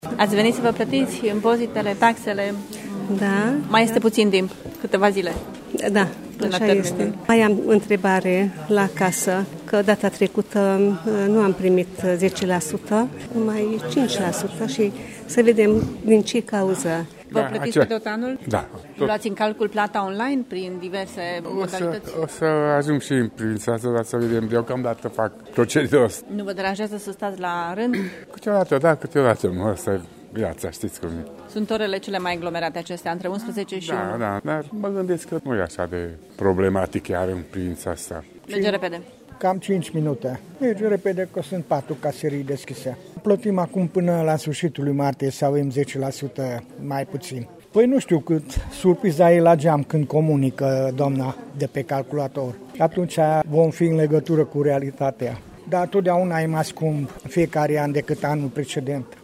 Aproximativ 30 de persoane așteptau la rând astăzi, în jurul prânzului, la ghișeele din Primăria Târgu Mureș. Oamenii nu sunt deranjați că trebuie să aștepte: